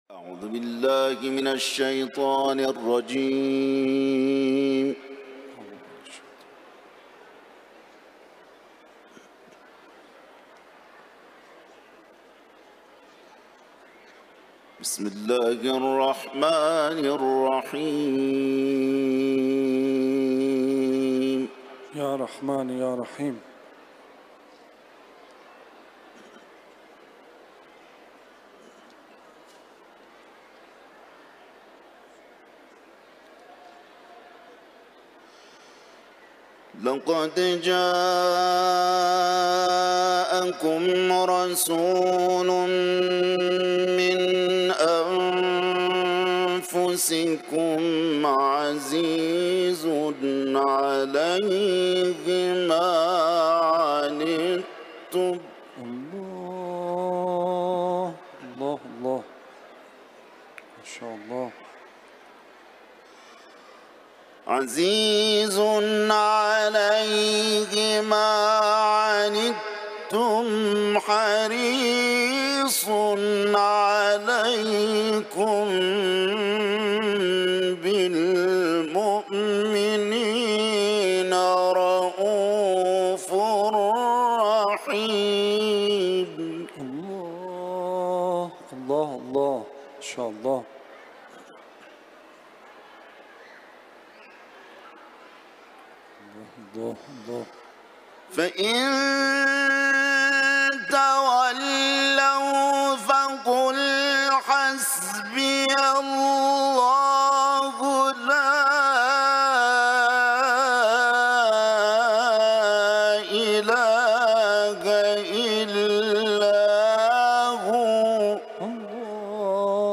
در حرم مطهر رضوی
سوره توبه ، تلاوت قرآن